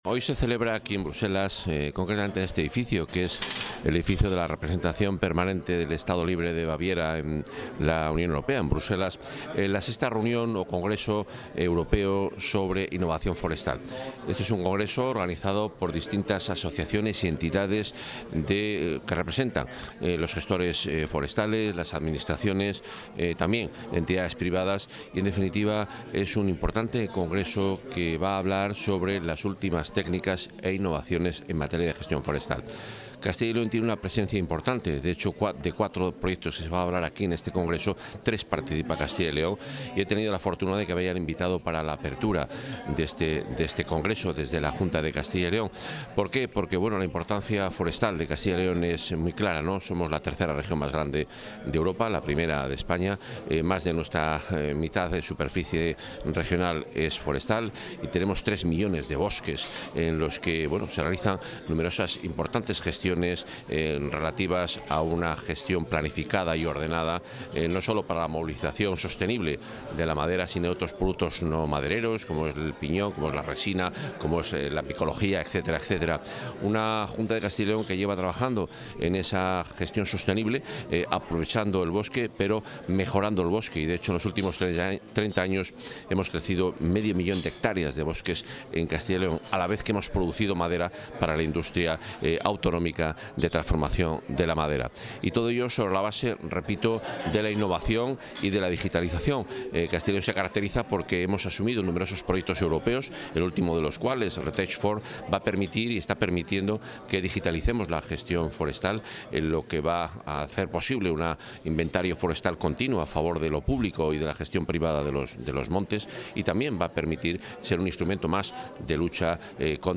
Declaraciones del consejero.
Suárez-Quiñones ha participado esta mañana en la inauguración de la 6ª Edición del Forest Innovation Workshop and OptFor-EU Mid-Term Conference, que se celebra en Bruselas. Durante su intervención, el consejero ha destacado el papel estratégico del sector forestal en la Comunidad y el compromiso de la Junta con el desarrollo sostenible a través de la innovación y la colaboración regional y europea.